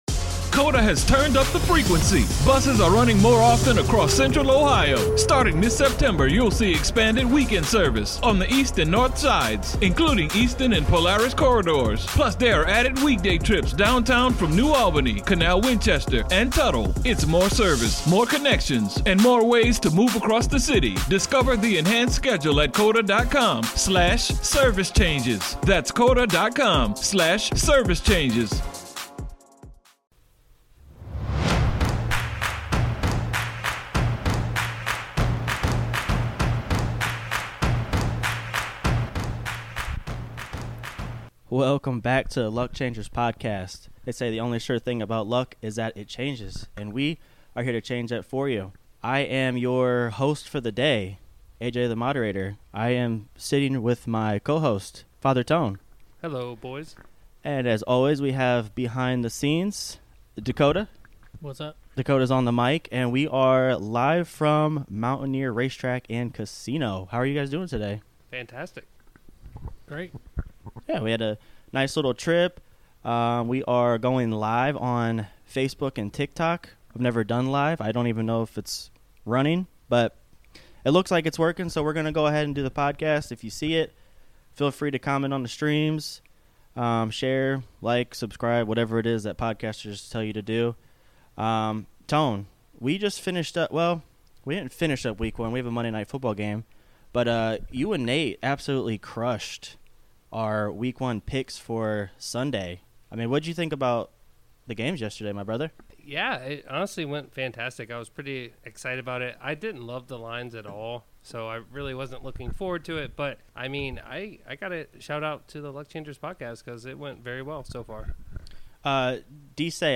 The boys are live from Mountaineer Race Track and Casino.